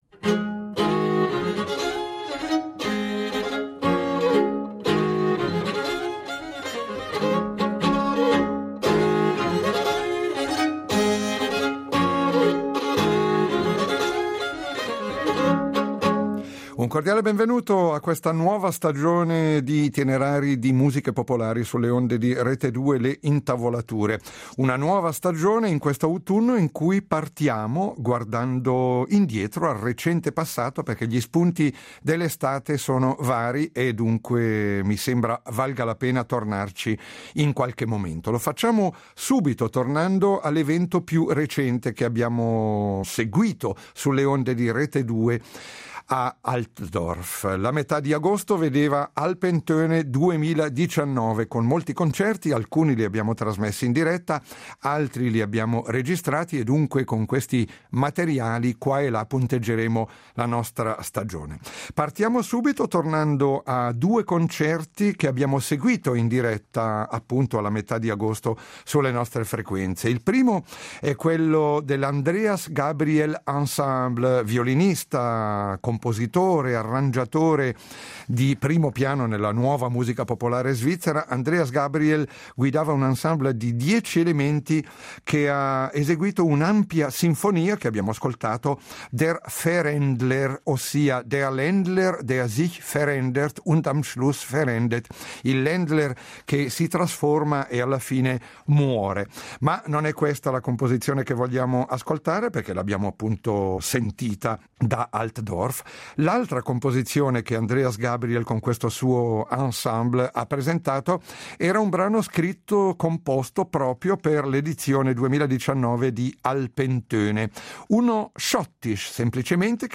nuova musica popolare dell’arco alpino
Ascolteremo alcuni brani da due bei concerti con
gruppo di 10 elementi guidato dal violinista svizzero